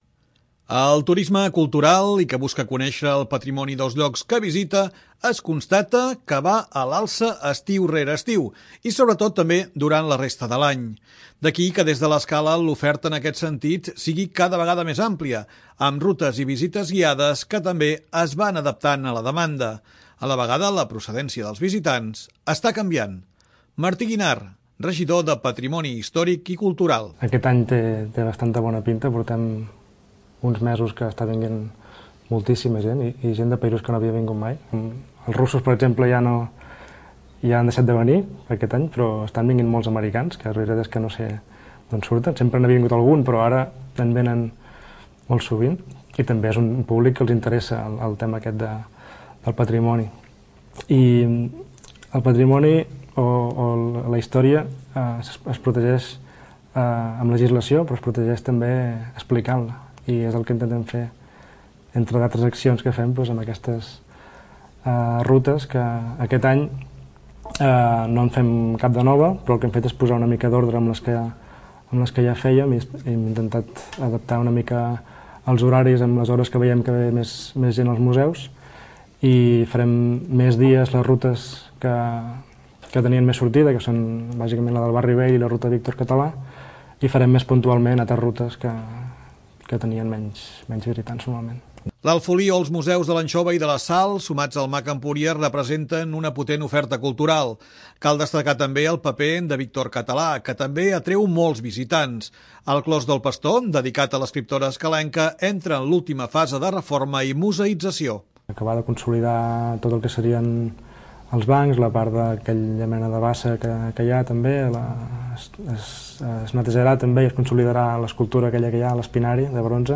L'oferta de visites culturals al municipi es varia de cara a aquest estiu. S'ha analitzat quina és la demanada i s'ha adaptat la oferta. És un dels temes tractats a la Casa de la Vila de Canal 10 amb el regidor de Patrimoni Martí Guinart.